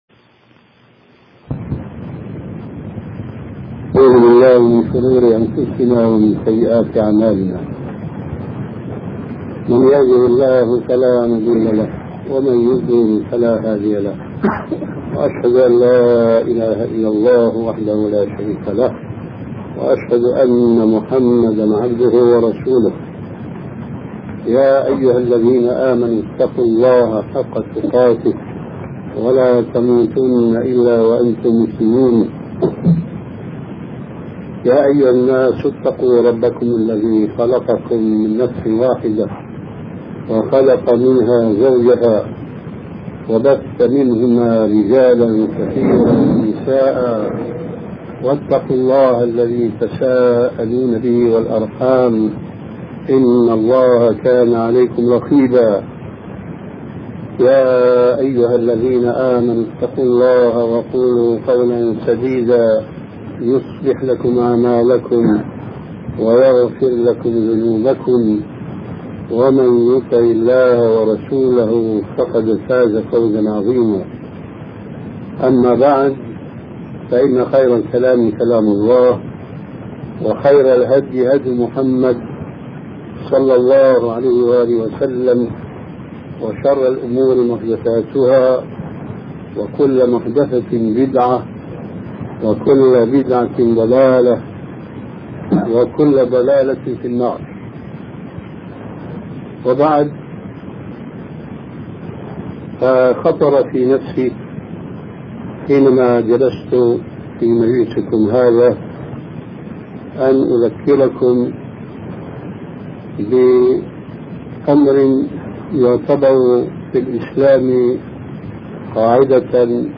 شبكة المعرفة الإسلامية | الدروس | آداب طالب العلم |محمد ناصر الدين الالباني